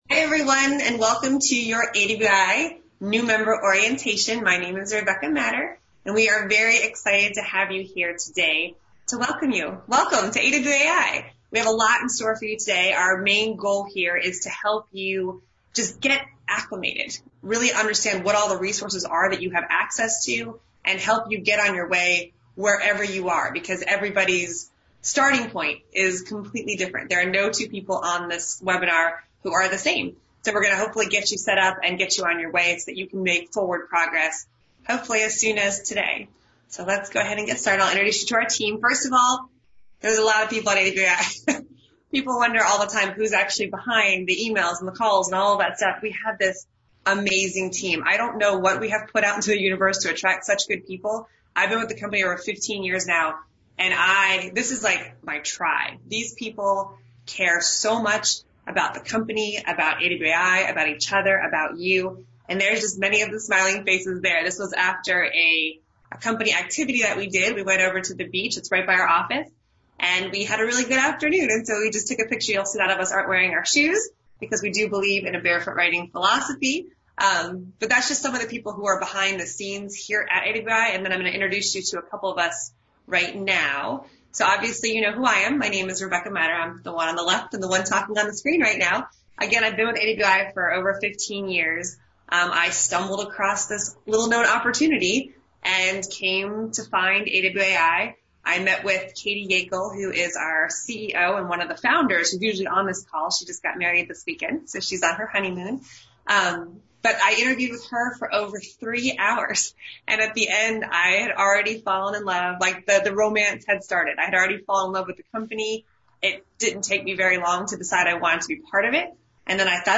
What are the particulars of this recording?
AWAI New Member Orientation Live Session Recording Here’s the recording of our most recent live session, along with the session slides, audio file, and Q&A transcript.